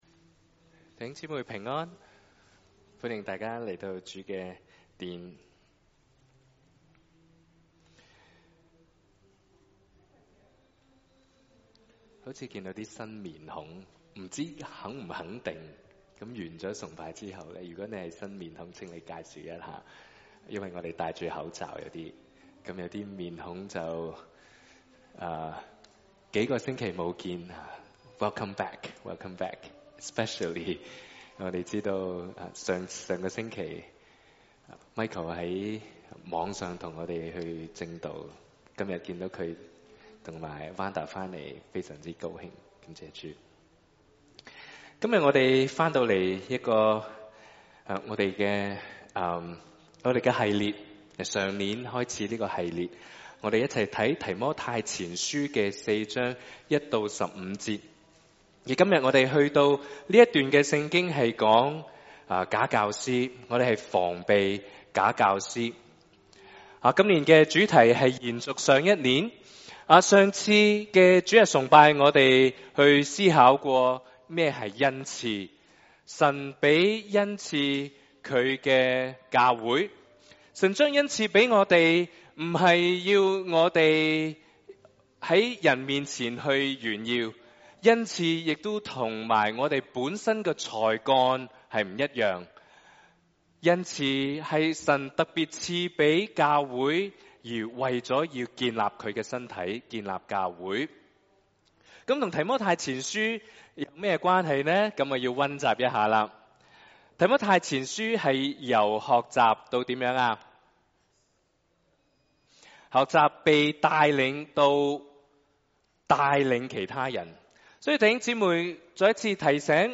1/15/2023 粵語崇拜講題: 「防備假教師」